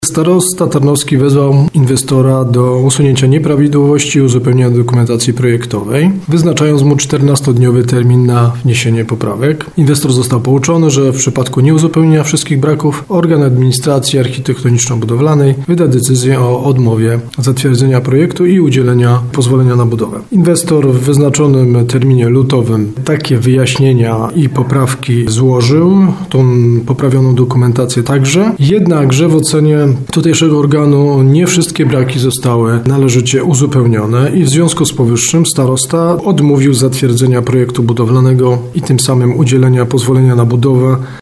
Jak mówi Radiu RDN Małopolska Tomasz Stelmach z zarządu Powiatu, powodem były braki w dokumentacji.